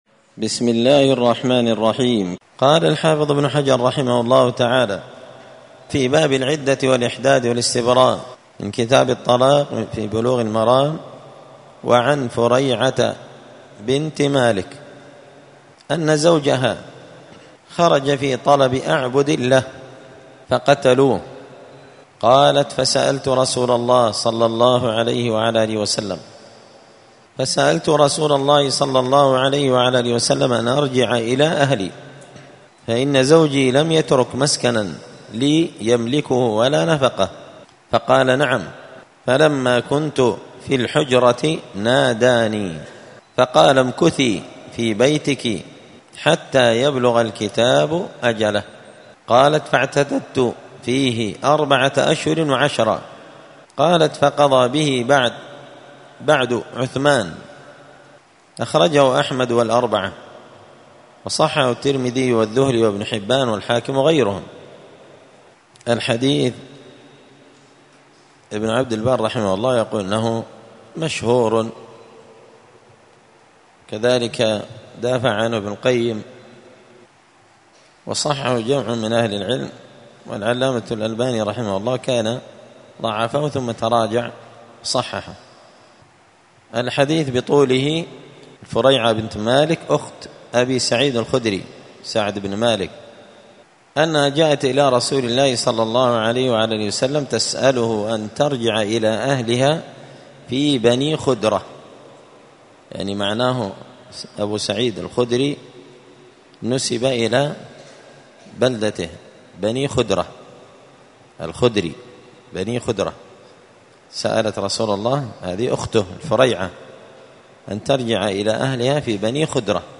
*الدرس الثاني والعشرون (22) {تابع لباب العدة الإحداد والاستبراء}*